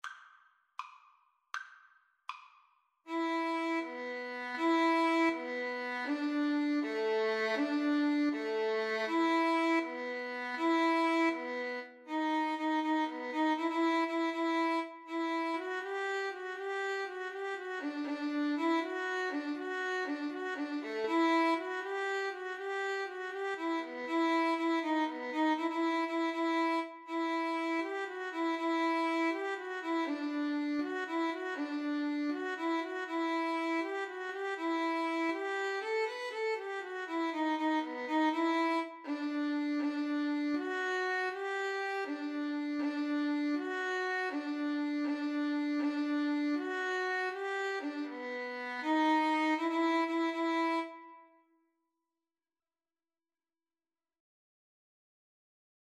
Free Sheet music for Violin Duet
Violin 1Violin 2
E minor (Sounding Pitch) (View more E minor Music for Violin Duet )
6/8 (View more 6/8 Music)
Traditional (View more Traditional Violin Duet Music)
Irish